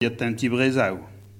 Sallertaine ( Plus d'informations sur Wikipedia ) Vendée
Locutions vernaculaires